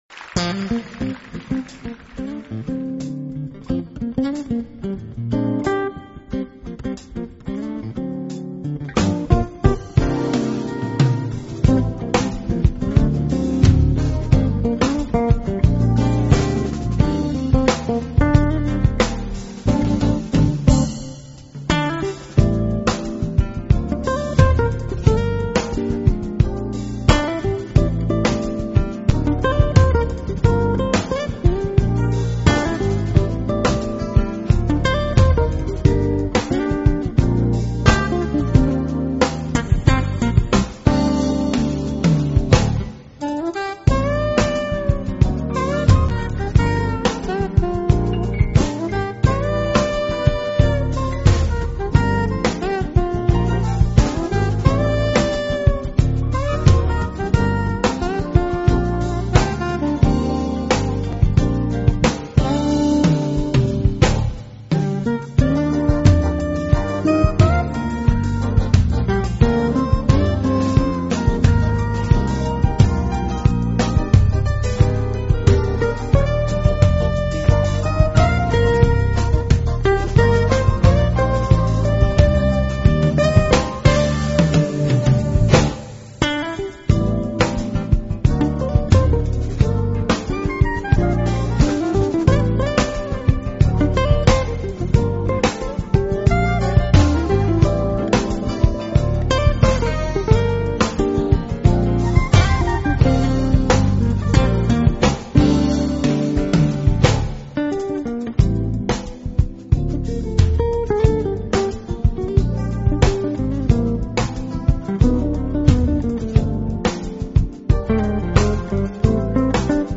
专辑类型：Smooth Jazz